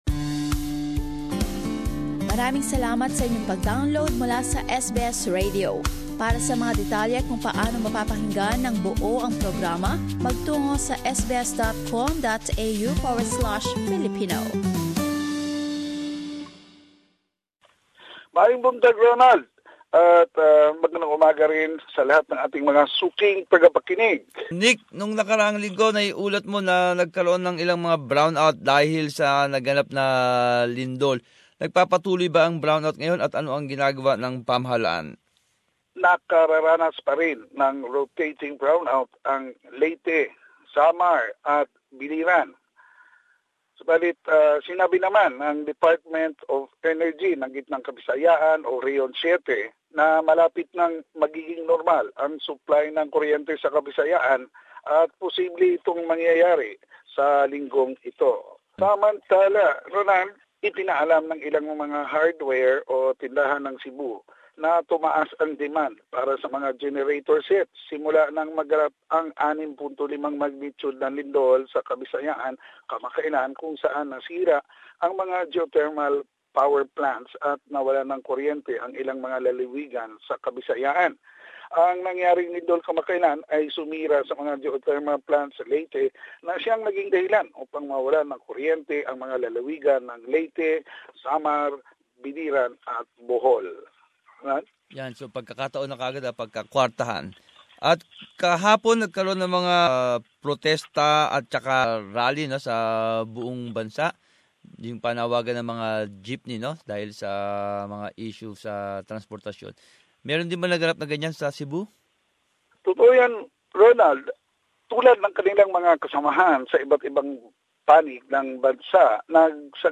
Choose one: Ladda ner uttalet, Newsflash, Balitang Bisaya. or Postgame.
Balitang Bisaya.